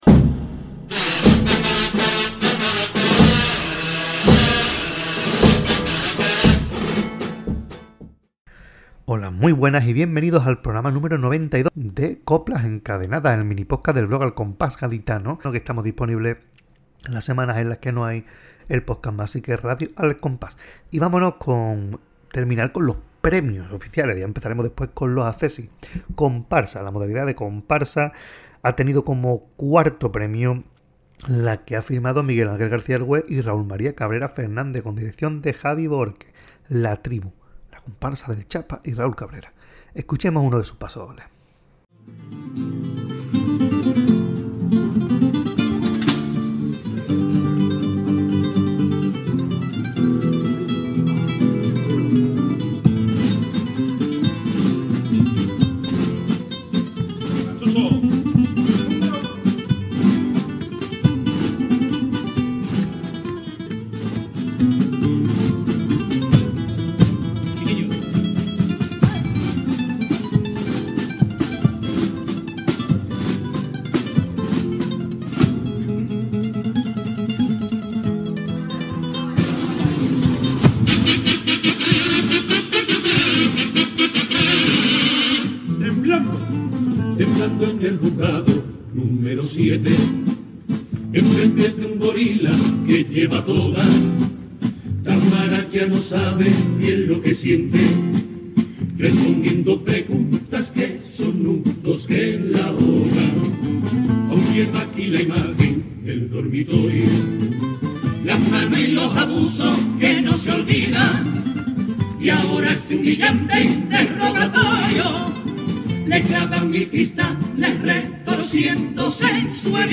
Pasodoble